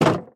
Minecraft Version Minecraft Version 1.21.5 Latest Release | Latest Snapshot 1.21.5 / assets / minecraft / sounds / block / fence_gate / close2.ogg Compare With Compare With Latest Release | Latest Snapshot